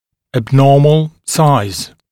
[æb’nɔːml saɪz][эб’но:мл сайз]атипичный размер